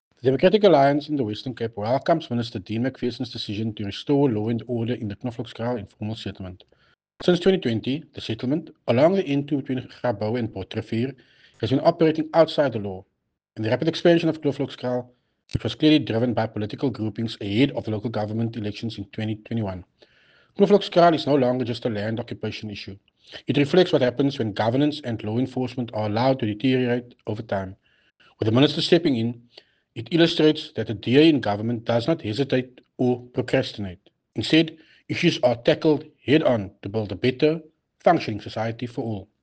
soundbite by Tertuis Simmers